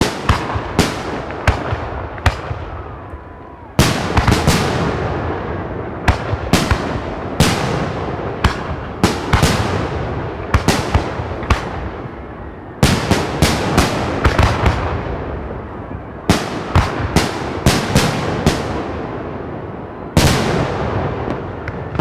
Звуки салюта
Звук разрывов фейерверка под открытым небом